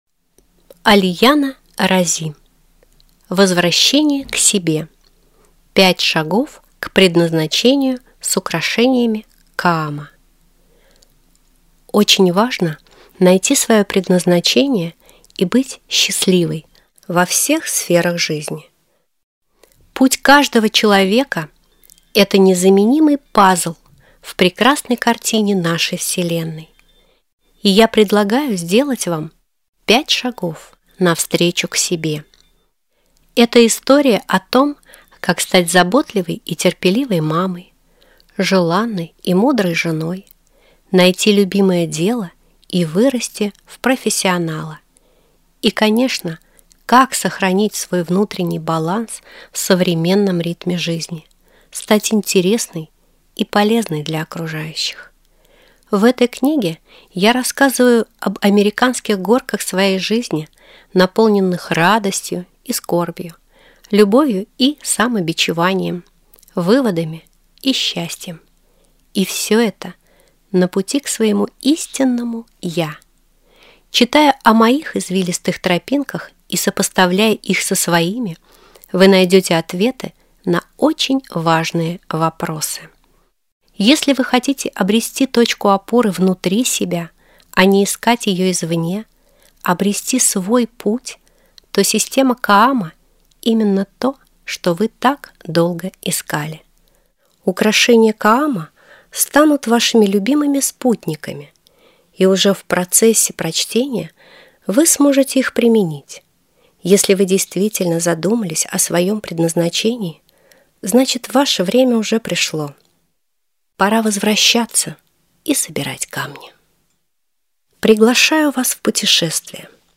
Аудиокнига Возвращение к себе. 5 шагов на пути к предназначению | Библиотека аудиокниг